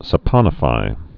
(sə-pŏnə-fī)